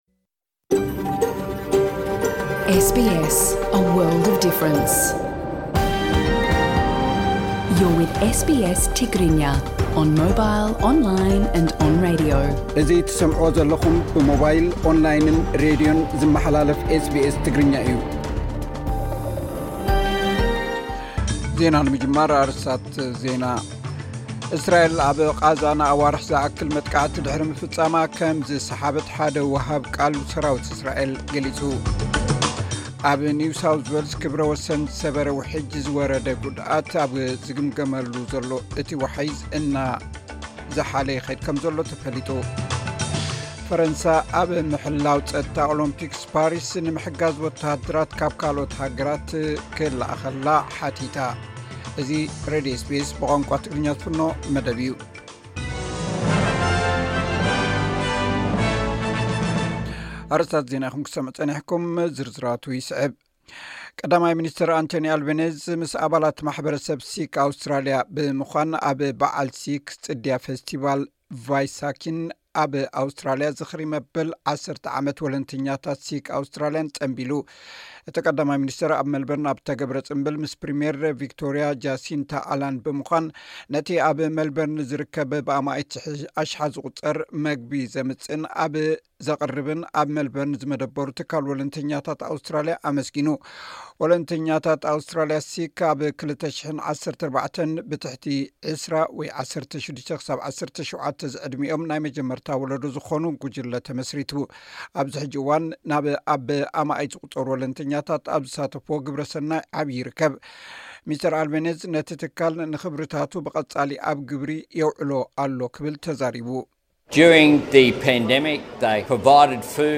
ዕለታዊ ዜና ኤስ ቢ ኤስ ትግርኛ (08 ሚያዝያ 2024)